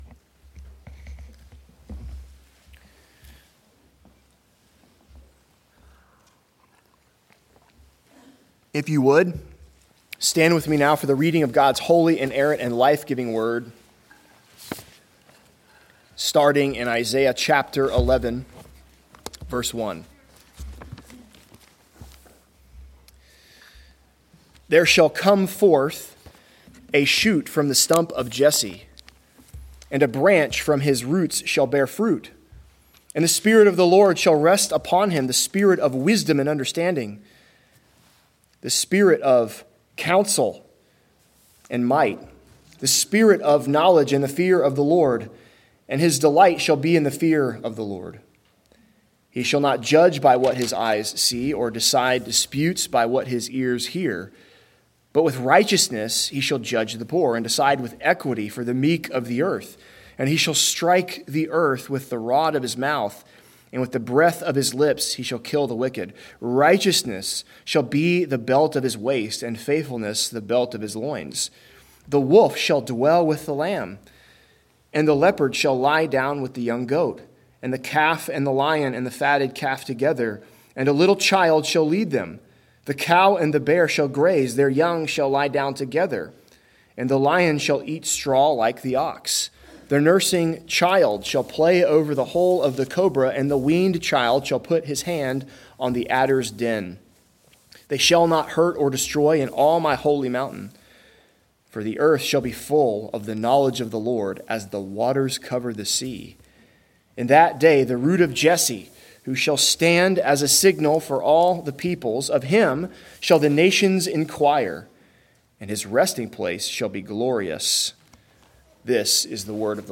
Galatians 2.11-14 Service Type: Sunday Worship Big Idea